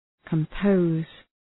Προφορά
{kəm’pəʋz}